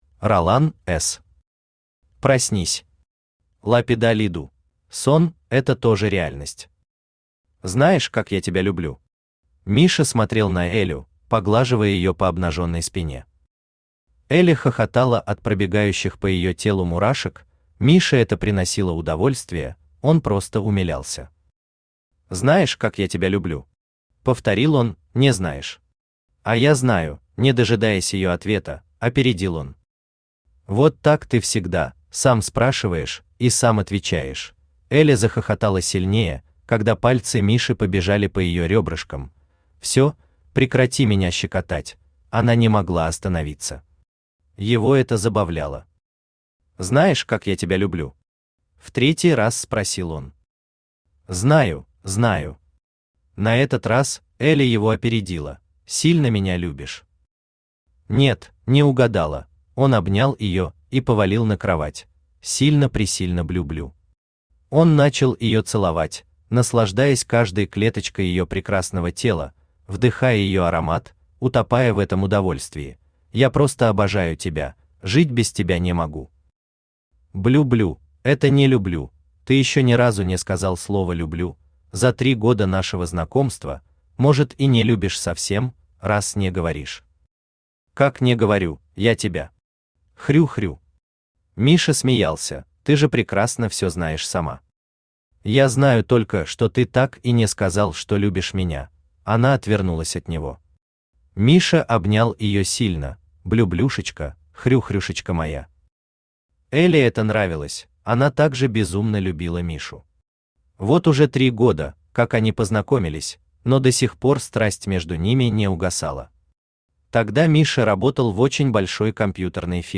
Аудиокнига Проснись! Ла пида лиду!
Автор Ролан Шакирович Эс Читает аудиокнигу Авточтец ЛитРес.